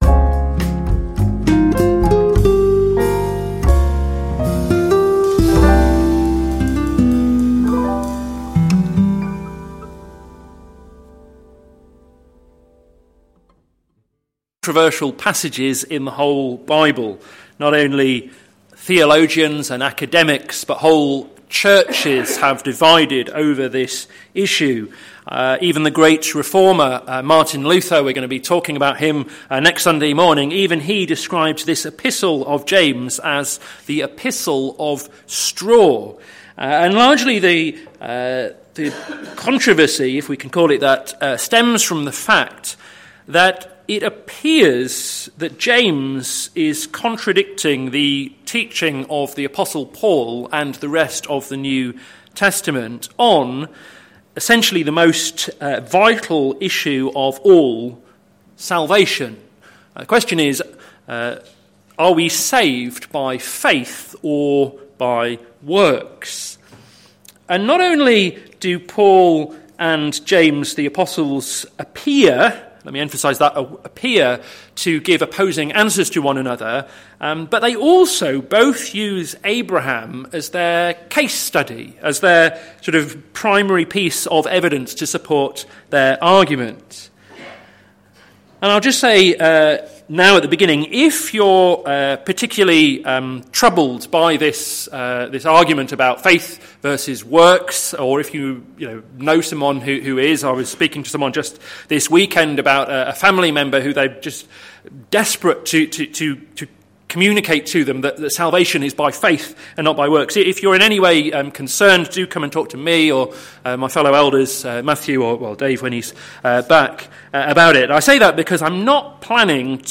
Sermon Series - Caught in Two Minds - plfc (Pound Lane Free Church, Isleham, Cambridgeshire)